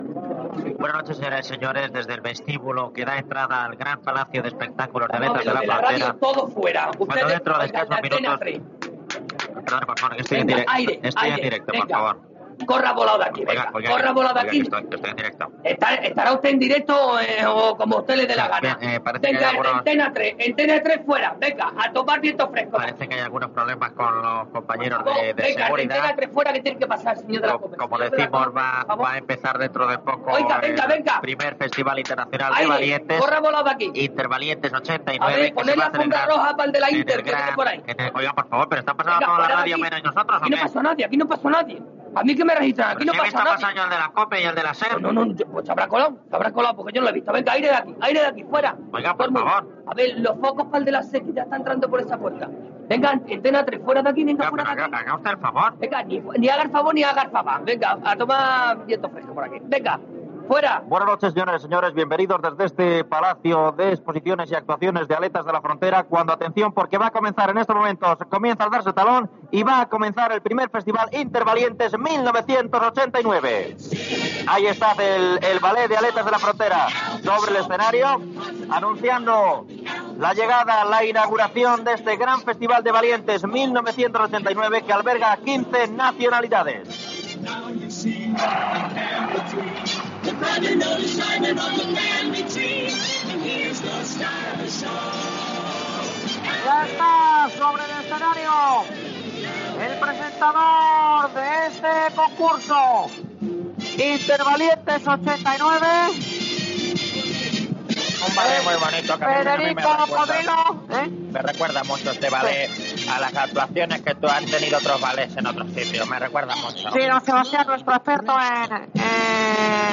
Transmissió des del I Festival Intervalientes 1989
Entreteniment
Gomaespuma era un duo còmic format per Juan Luis Cano i Guillermo Fesser.